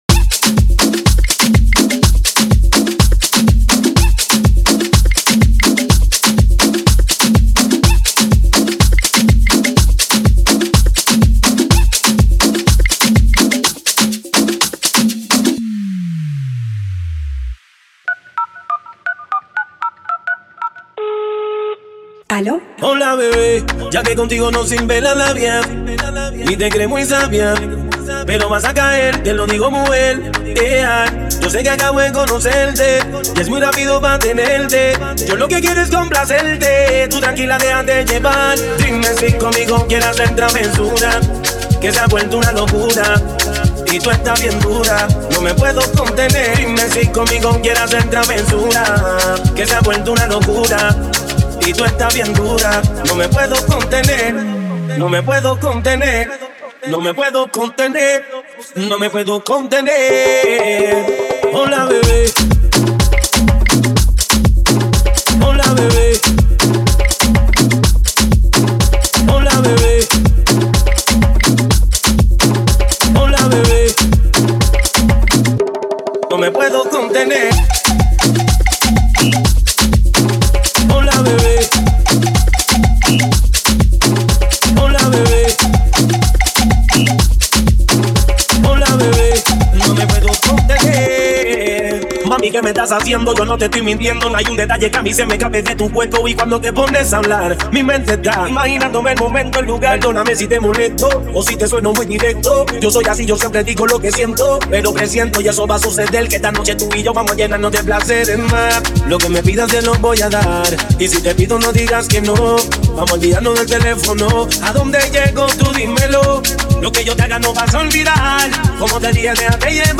Feel the Heat of Afro and Latin Rhythms in Miami